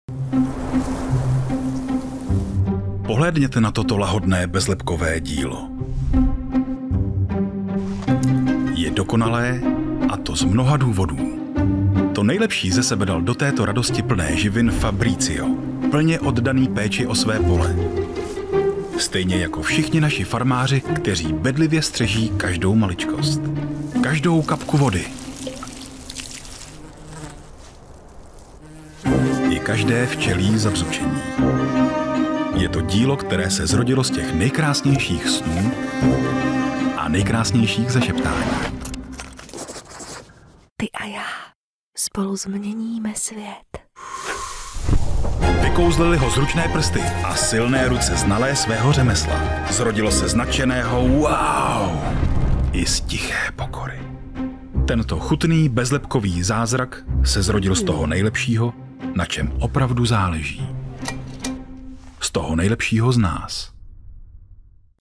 Součástí každého jobu je i základní postprodukce, tedy odstranění nádechů, filtrování nežádoucích frekvencí a ekvalizace a nastavení exportu minimálně 48kHz/24bit, okolo -6dB, jestli se nedohodneme jinak.
Mužský voiceover - hlas do krátkých reklamních spotů!